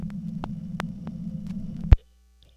Record Noises
Record_End_Short.aif